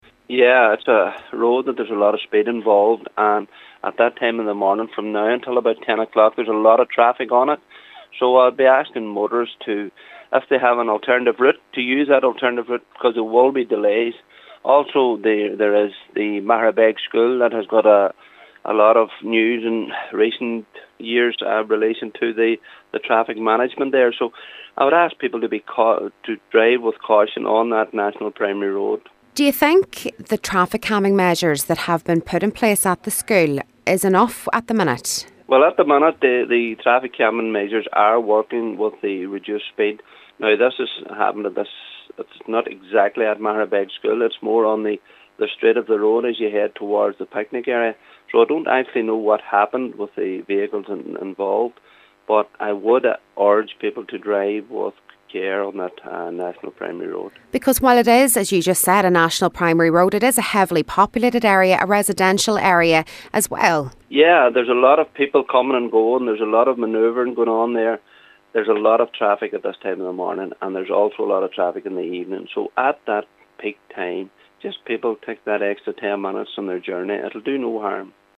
Local Cllr Paul Canning says the particular stretch of road has been subject to a high number of collisions over the years: